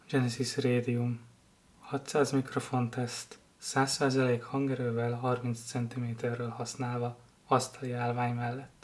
Hangminőség teszt: csendes környezet
Hogy hallható legyen a zaj, amit összeszed, kipróbálásra került csendes környezetben is.